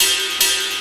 Ride 08.wav